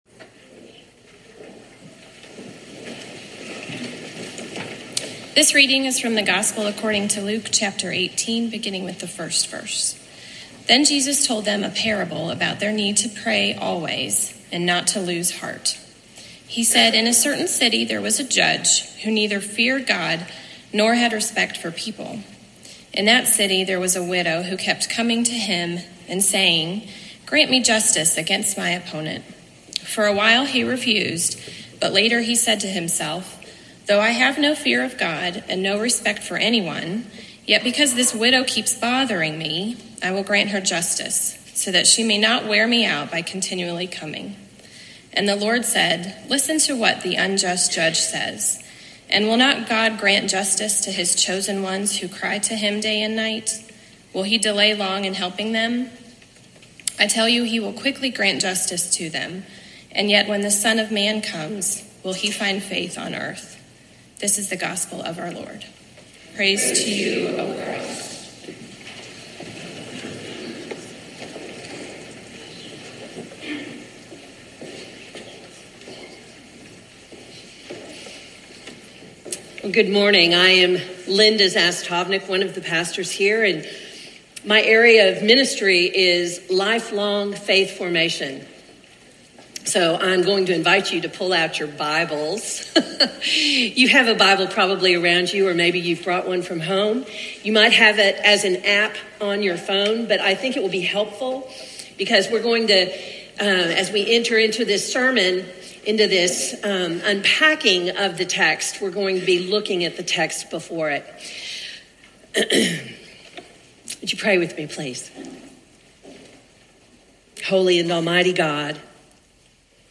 Faith is a lifelong response to the call of God. Faith is living in the presence of God and hoping for God’s future, leaning into the coming kingdom that God has promised. (Worship service at First United Methodist Church * Davidson, NC * Oct. 21, 2019).